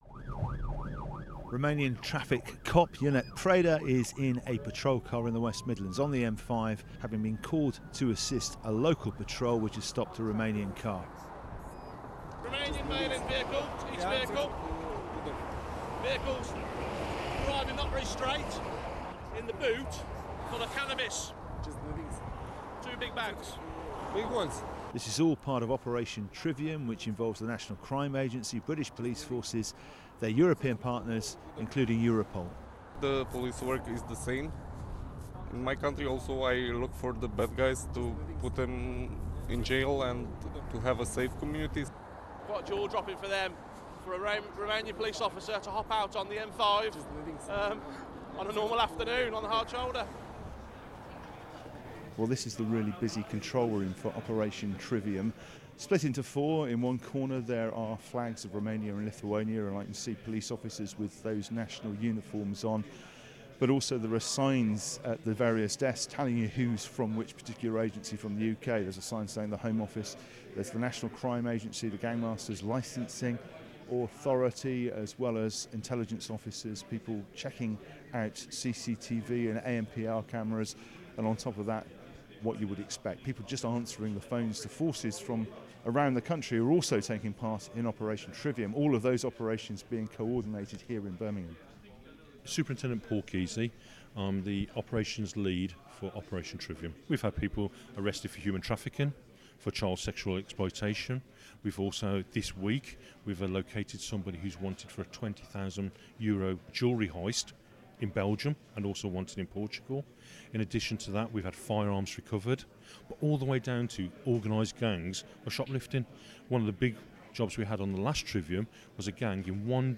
I joined officer on patrol & in the control room in Birmingam